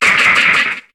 Cri de Ludicolo dans Pokémon HOME.